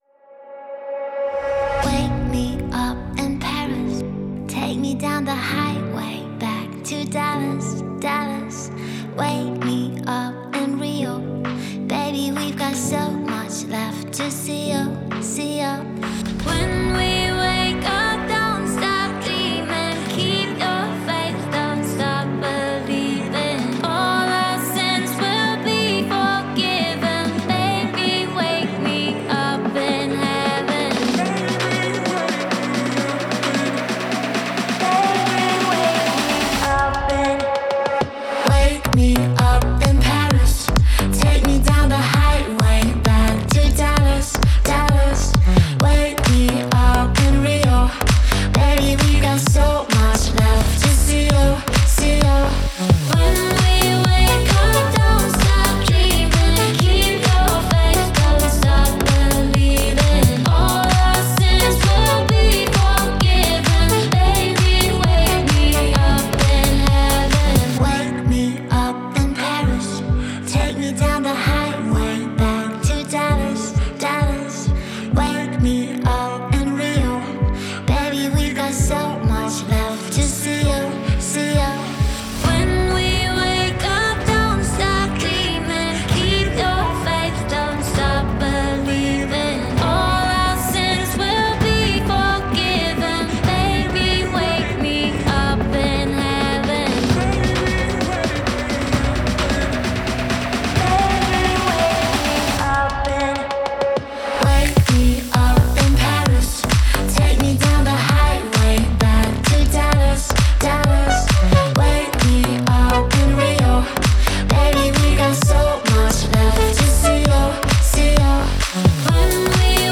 это яркая и энергичная композиция в жанре EDM